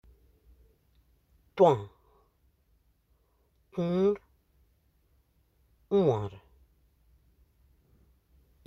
Accueil > Prononciation > û > û